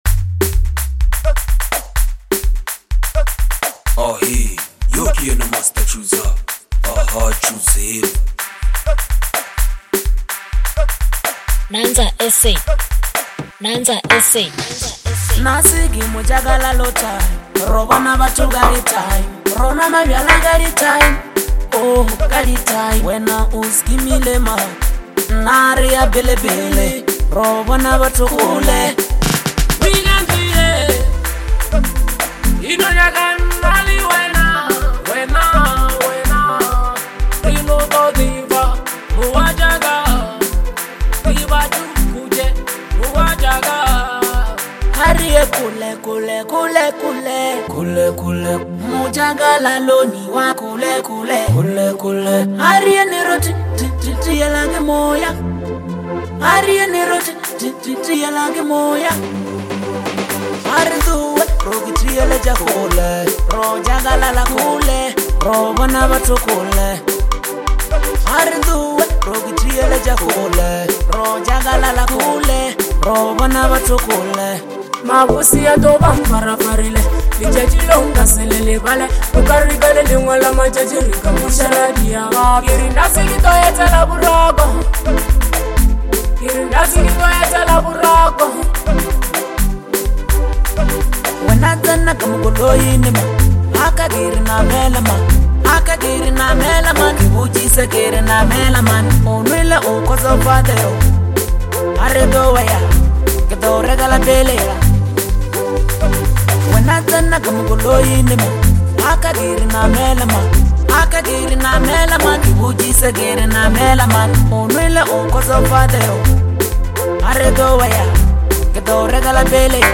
is a lively and celebratory track
high energy rhythm